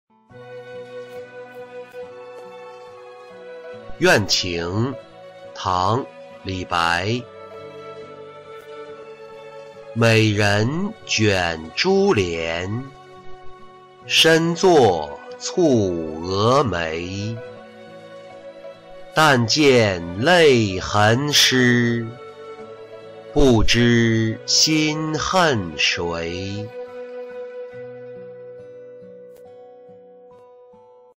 中夜起望西园值月上-音频朗读